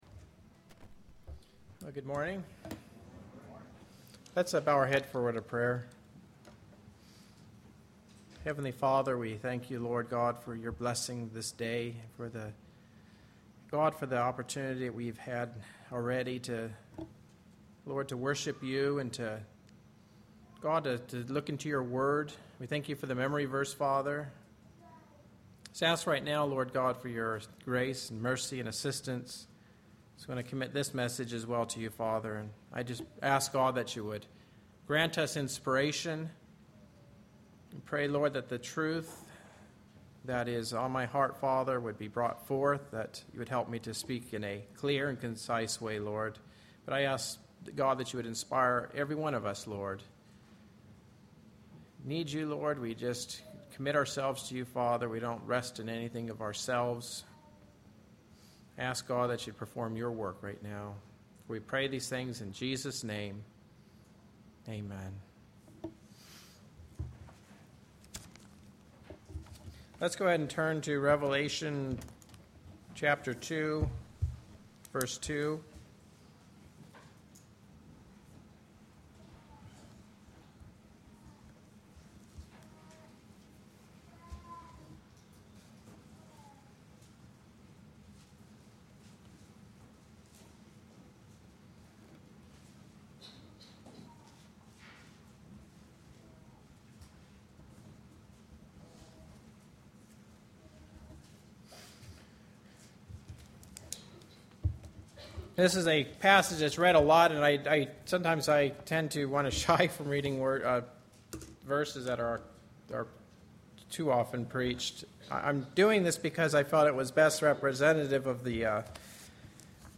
In this sermon, the preacher emphasizes the importance of faith that is demonstrated through love. He commends the Ephesians for their works, which were done with patience and for the sake of Christ's name.